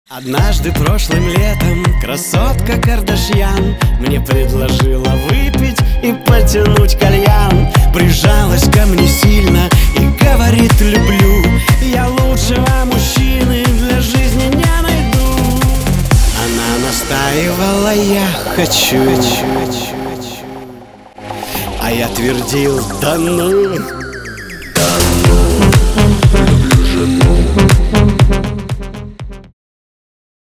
• Качество: 320, Stereo
мужской голос
веселые
цикличные